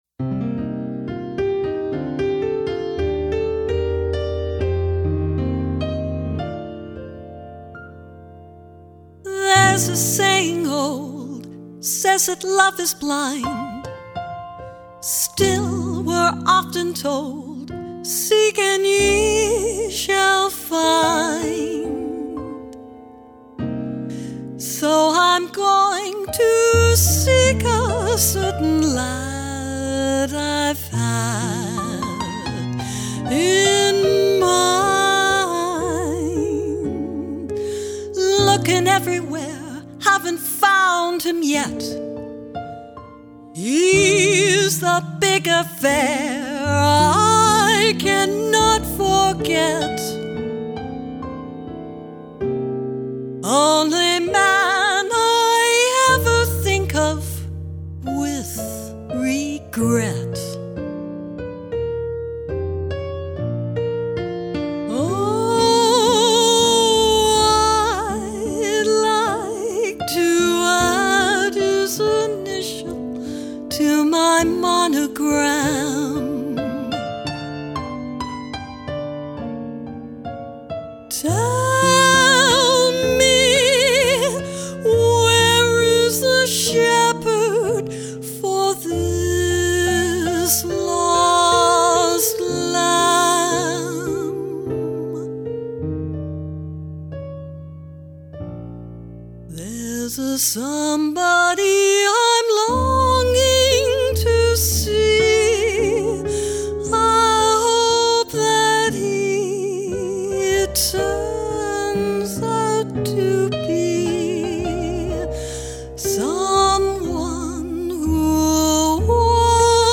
American Standards/Light Jazz
More Songs from The Great American Songbook